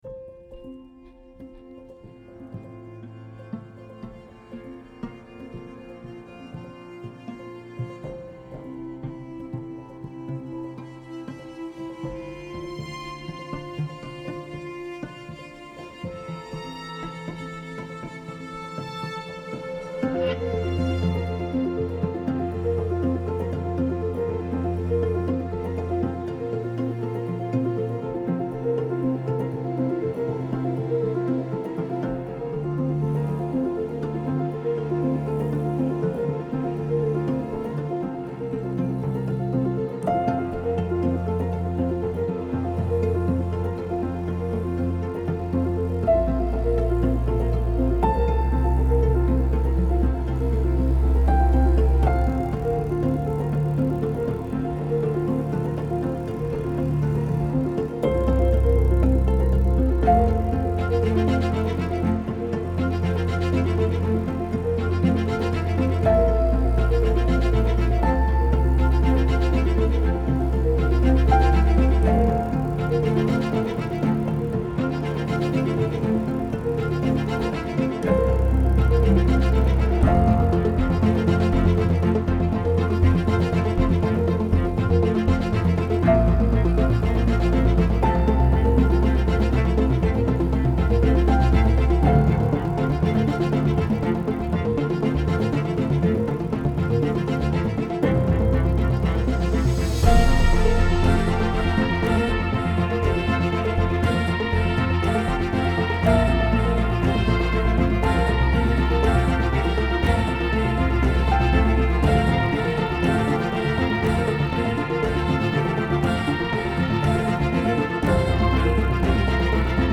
امبینت